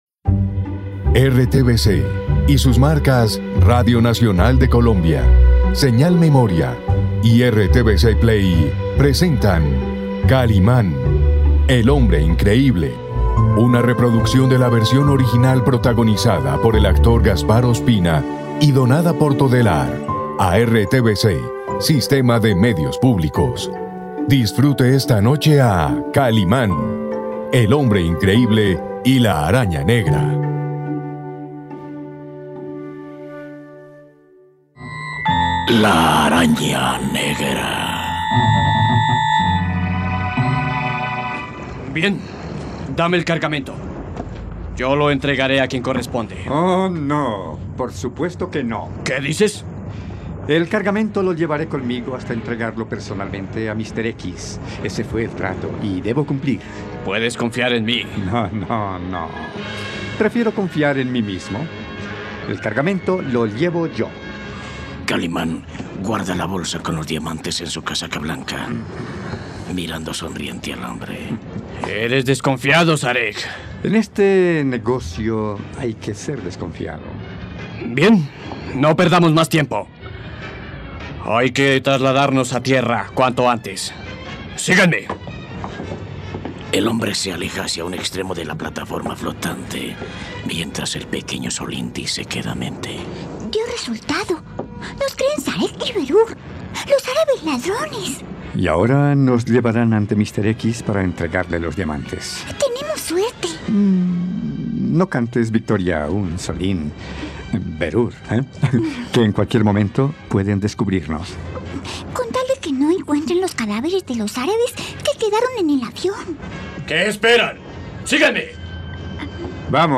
Podcast de ficción.
radionovela